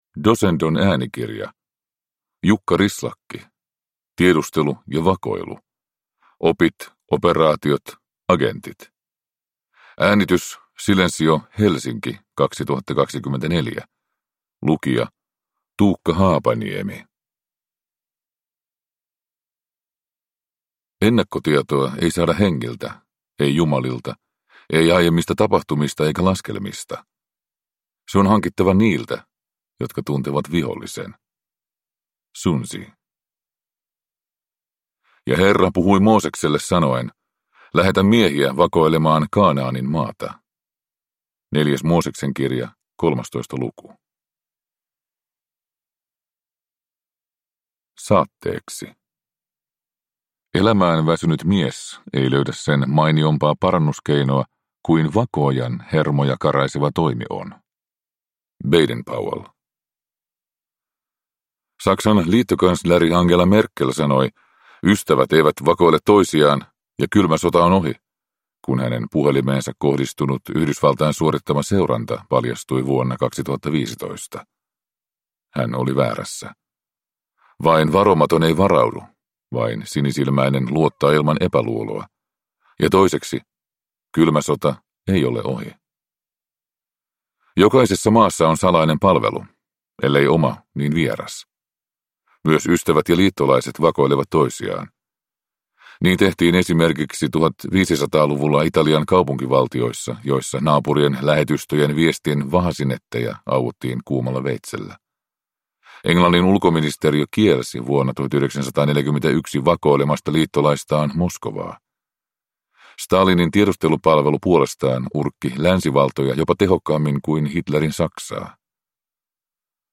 Tiedustelu ja vakoilu – Ljudbok